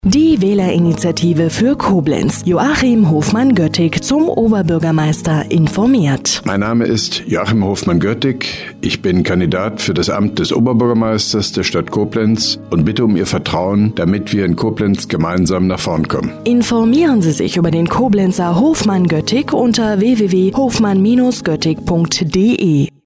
Rundfunkspot (1) der Wählerinitiative Hofmann-Göttig zum Oberbürgermeister für Koblenz